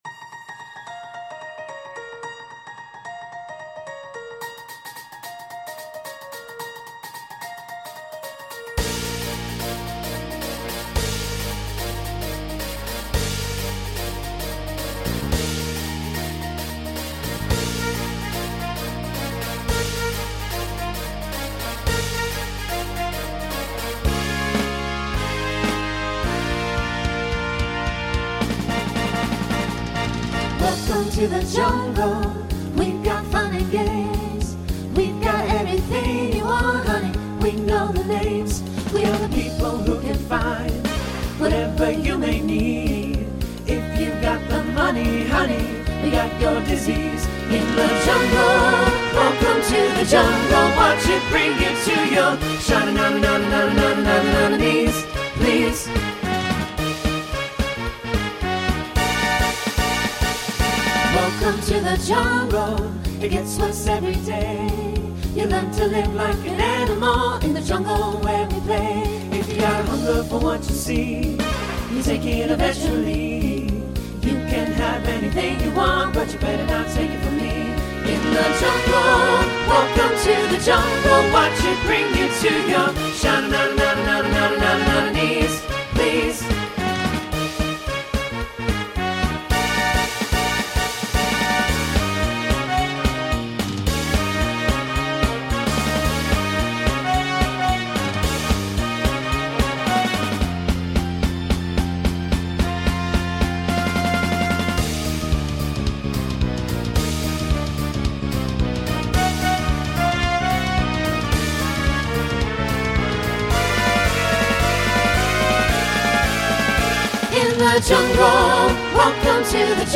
Voicing SATB Instrumental combo Genre Swing/Jazz
Mid-tempo